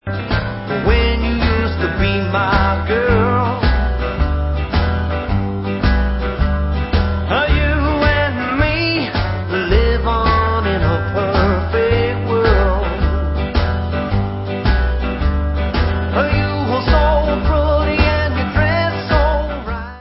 Counrty swing with rockabilly flavors